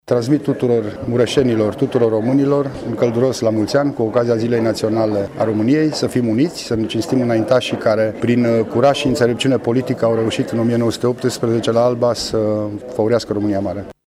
Prefectul județului Mureș, Lucian Goga.